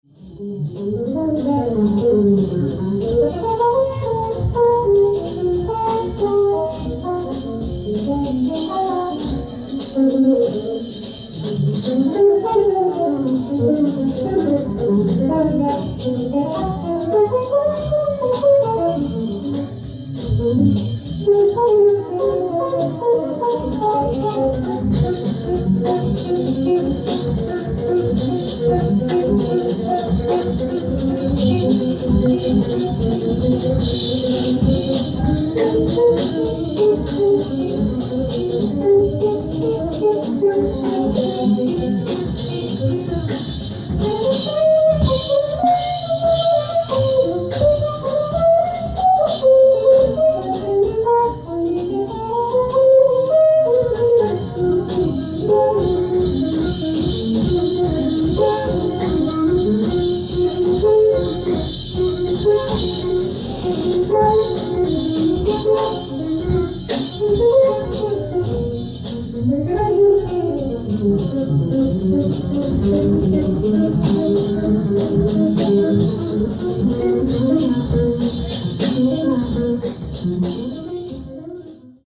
g-synth
tpt, flh